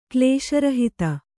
♪ klēśa rahita